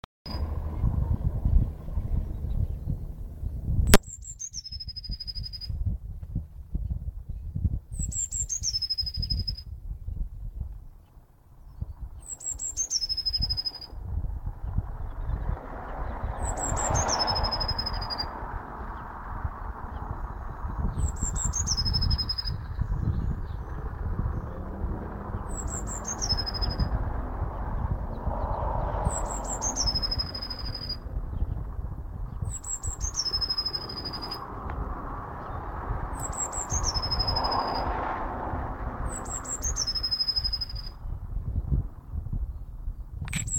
Anyone know what this bird is? It's song is pretty. Lovely walk home.